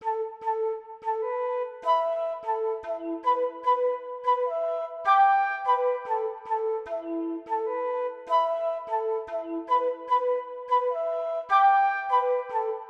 CookUP_Flute.wav